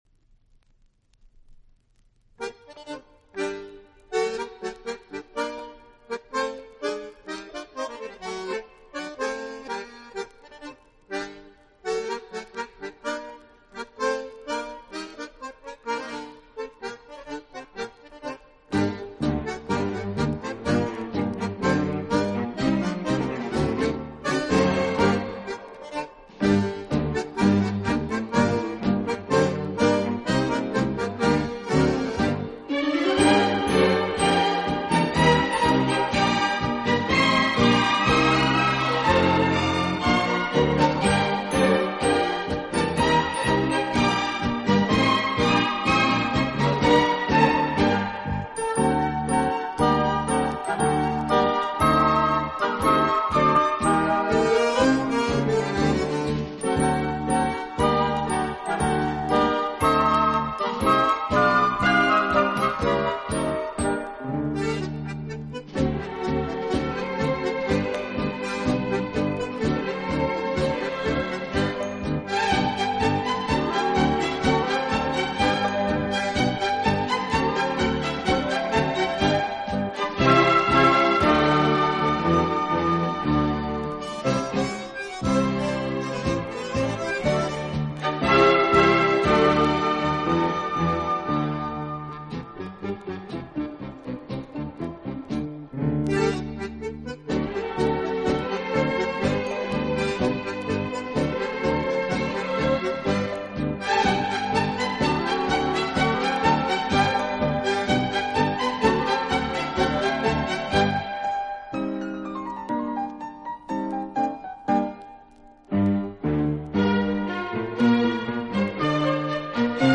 乐背景的不同，以各种乐器恰到好处的组合，达到既大气有力又尽显浪漫的效果。
乐队的弦乐柔和、优美，极有特色，打击乐则气度不凡，而手风琴、钢琴等乐器